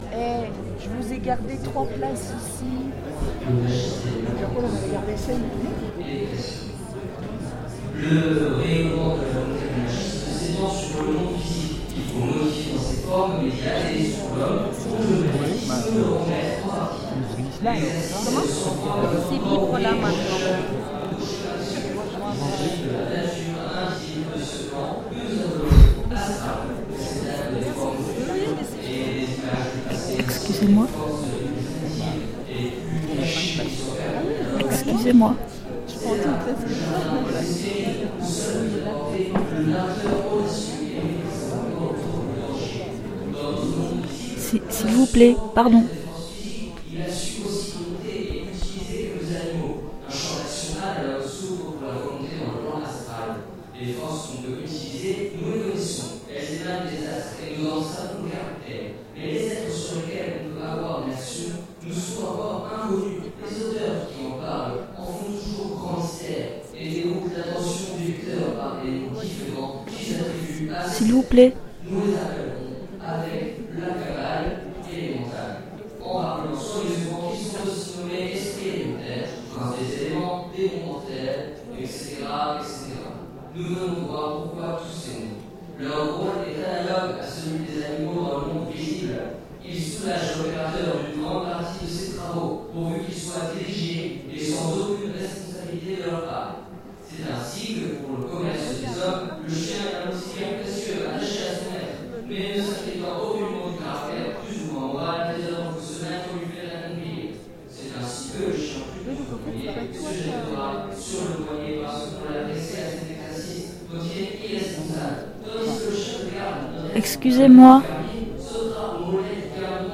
Avec les enregistrements en question, nous avons concocté des pièces sonores, avec adjonction de sons, musiques ou extraits de films… Enjoy!
Conférence Scientifique  (A base de : captation aux Etats Généraux du Film Documentaire de Lussas)